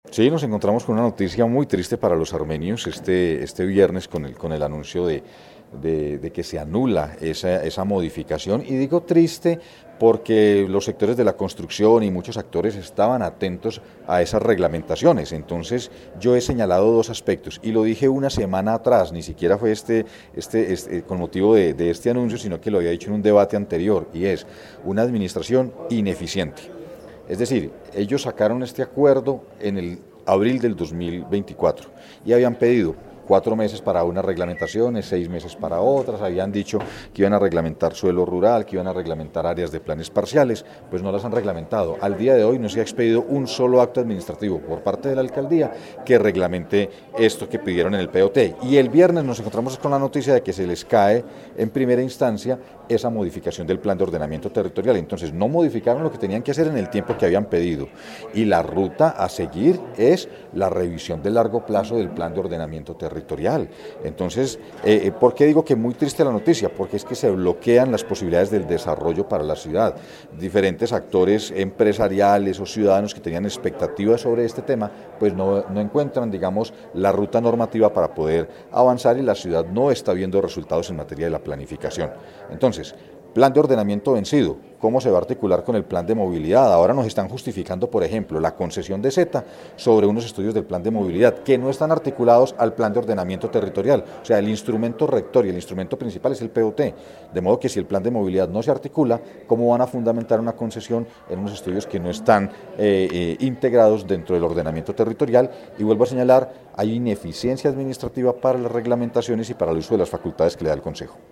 Concejal José Ignacio Rojas